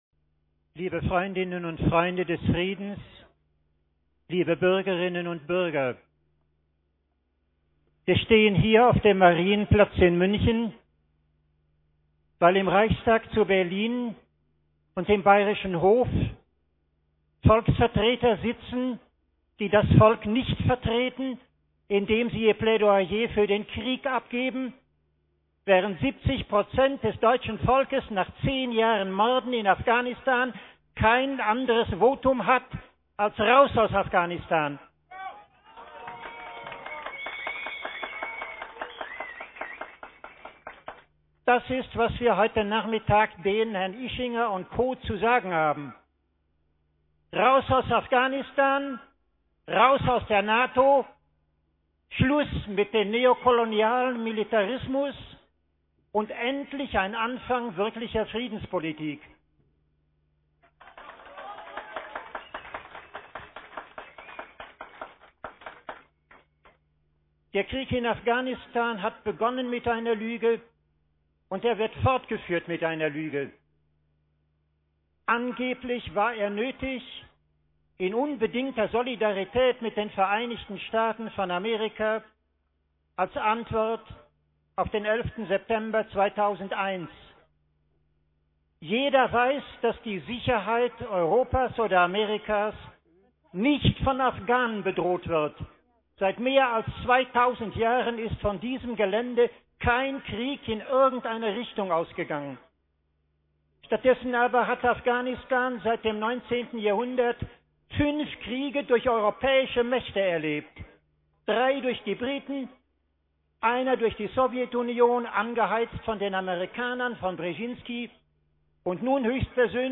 Samstag, 5.2.2011 Abschlusskundgebung auf dem Marienplatz * Download Audioaufzeichnung Rede Eugen Drewermann MP3 6.7 Mb * Download Redemanuskript Eugen Drewermann PDF 91k korr. 9.2.
Rede bei der Abschlusskundgebung der Demonstration gegen die Nato-Kriegstagung am 5. Februar 2011 auf dem Münchner Marienplatz